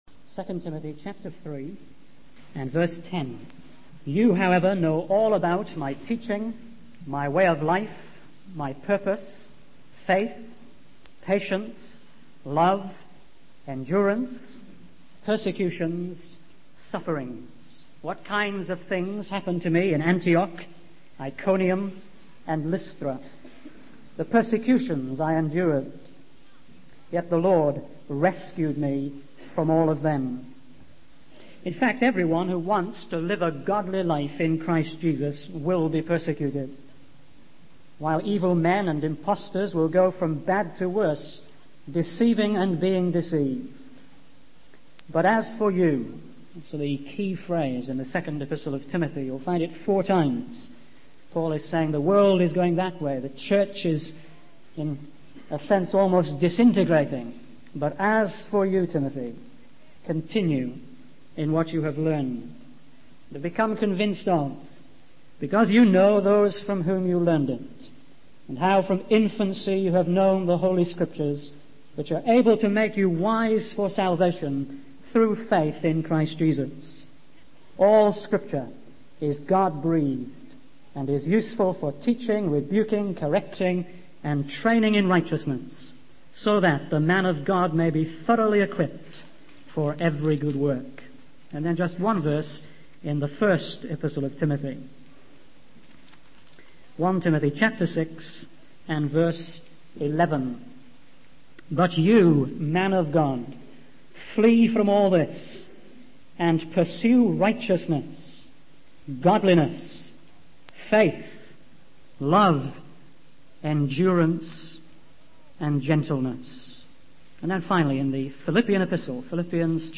In this sermon, the speaker emphasizes the importance of leading by example and living a life that aligns with the teachings of Jesus Christ.